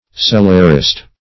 Cellarist \Cel"lar*ist\, n.